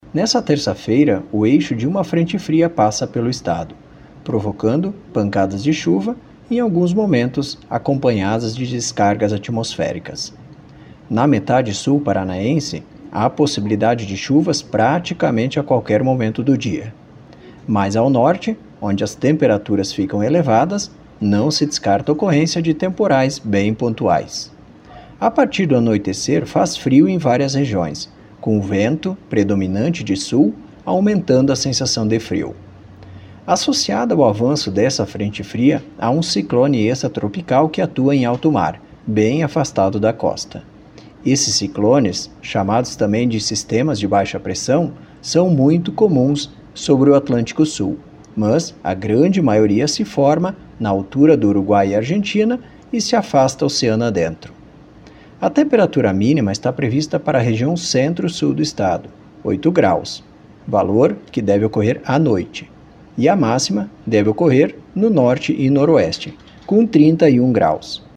Nesta terça, o eixo da frente fria avança pelo Paraná, provocando pancadas de chuvas, em alguns momentos acompanhadas de descargas elétricas. Ouça o que diz o meteorologista do Simepar